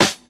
• Loud Snare Sound F Key 333.wav
Royality free acoustic snare sound tuned to the F note. Loudest frequency: 2754Hz
loud-snare-sound-f-key-333-9y4.wav